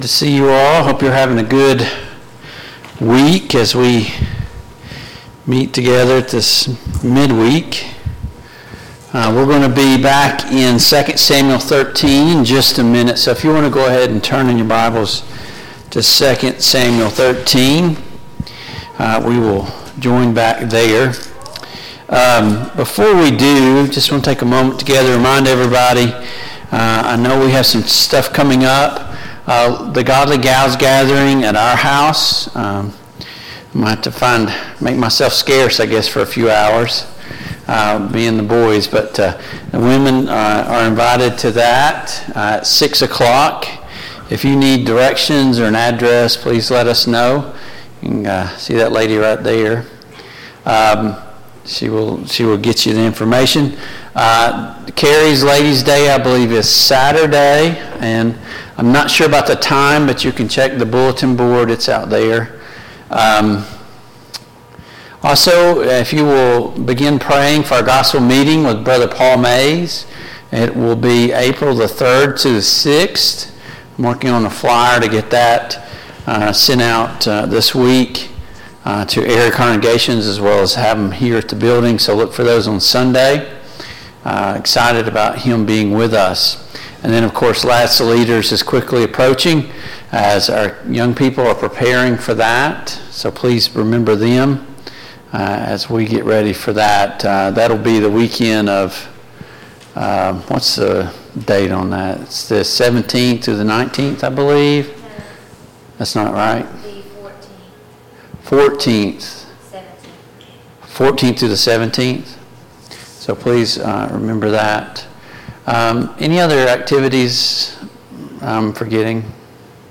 The Kings of Israel Passage: II Samuel 13, II Samuel 14 Service Type: Mid-Week Bible Study Download Files Notes « 1.